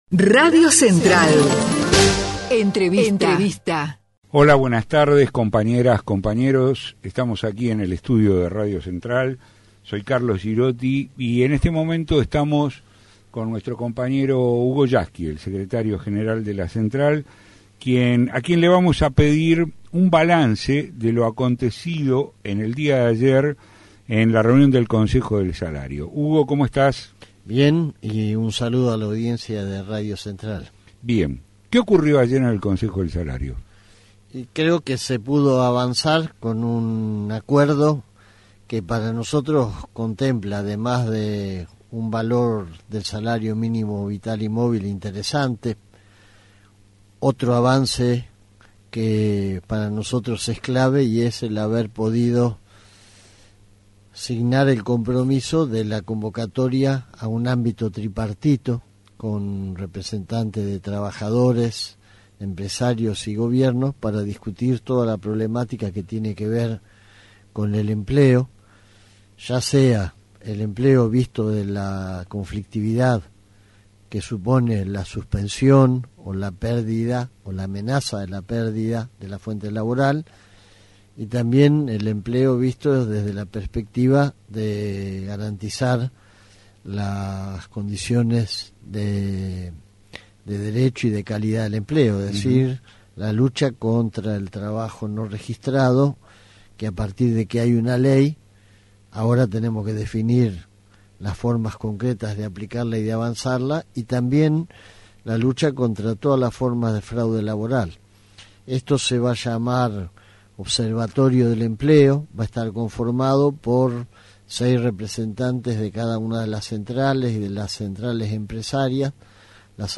HUGO YASKY (entrevista) RADIO CENTRAL - Consejo del salario
El secretario General de la Central de Trabajadores de la Argentina entrevistado